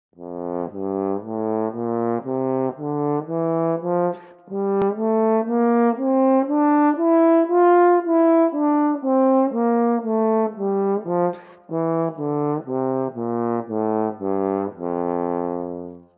Звуки тромбона
Тромбон – медный духовой инструмент с красивыми мелодиями в mp3 для монтажа видео